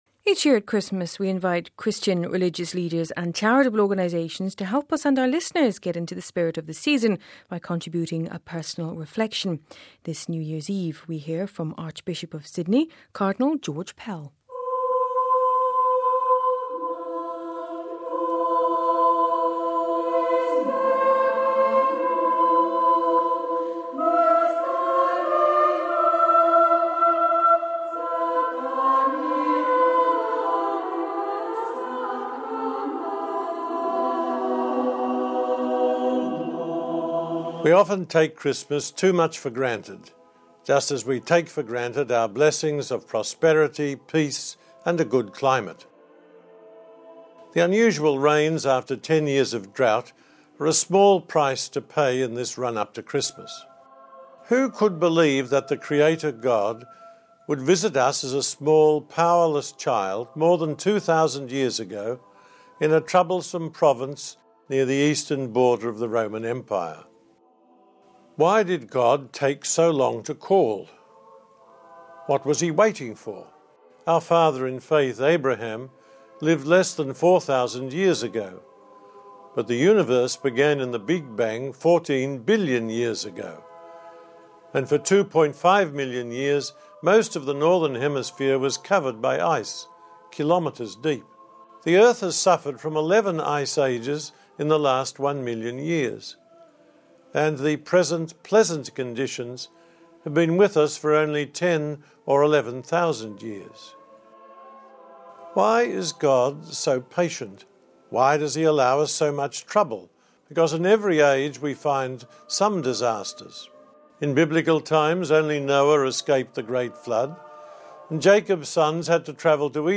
Each year at Christmas, we invite Christian religious leaders and charitable organizations to help us and our listeners get into the spirit of the season, by contributing a personal reflection. We hear from Archbishop of Sydney, Cardinal George Pell.